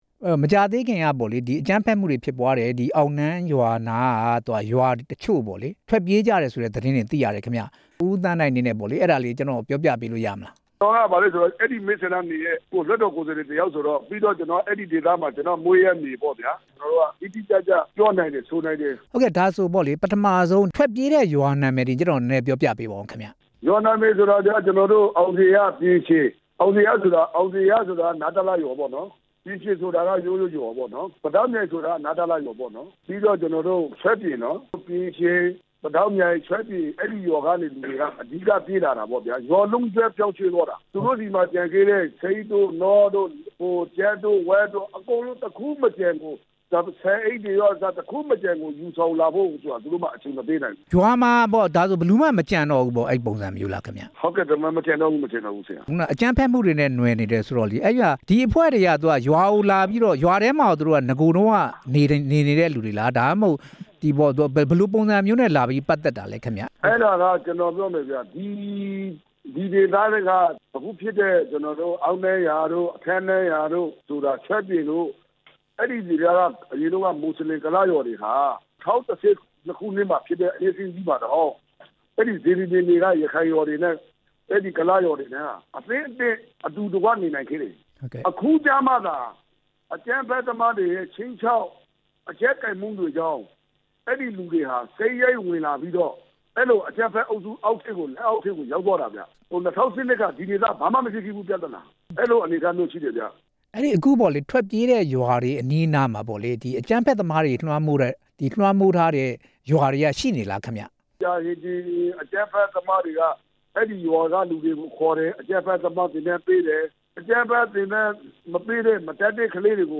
ဒေသအခြေအနေများ အကြောင်း ဆက်သွယ် မေးမြန်းထားပါတယ်။